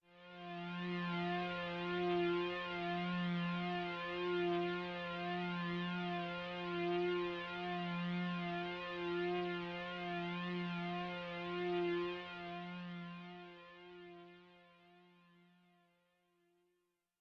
标签： MIDI-速度-98 ESharp4 MIDI音符-65 罗兰-HS-80 合成器 单票据 多重采样
声道立体声